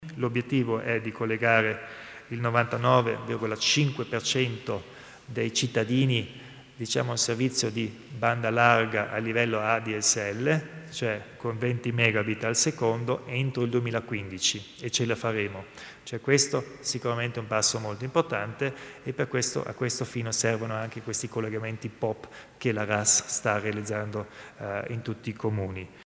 Il Presidente Kompatscher spiega il sostegno al progetto della banda larga